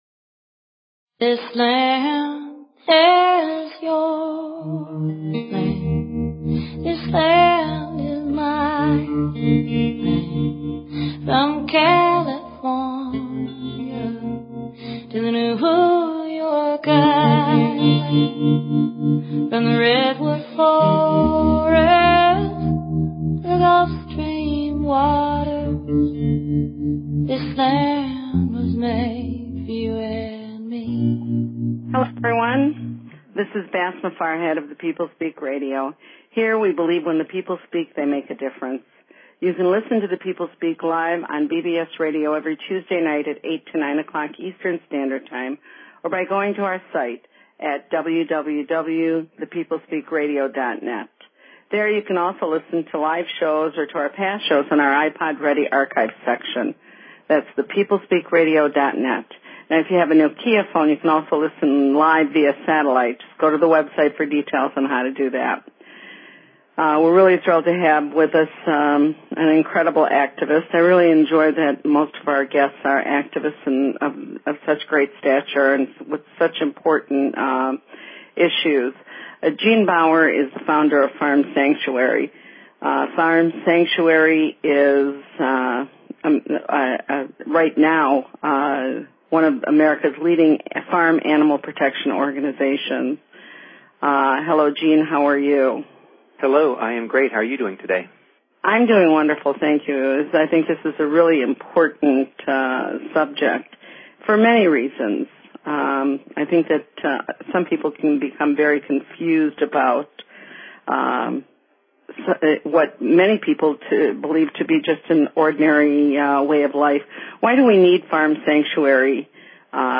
Guest, Joan Jett